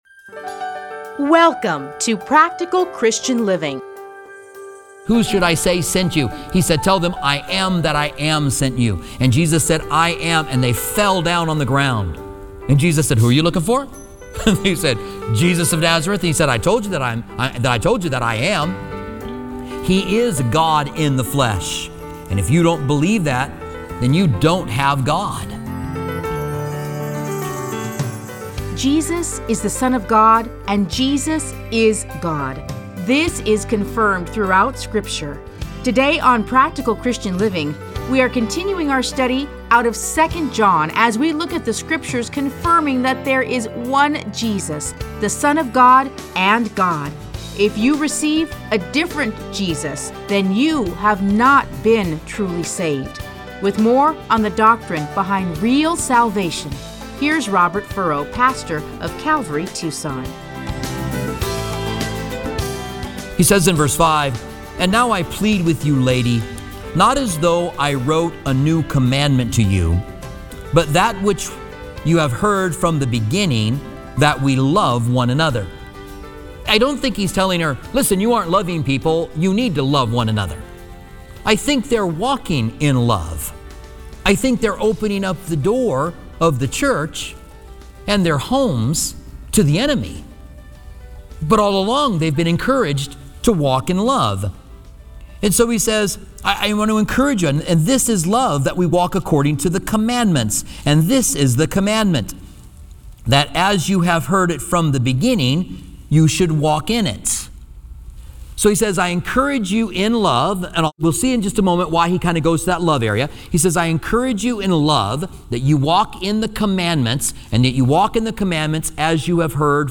Listen to a teaching from 2 John 1-13.